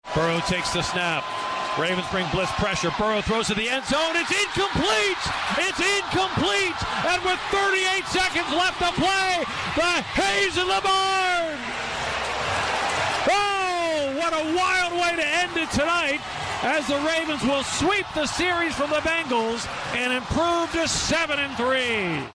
PBP-Bengals-34-35-Failed-2-Pt-Conversion.mp3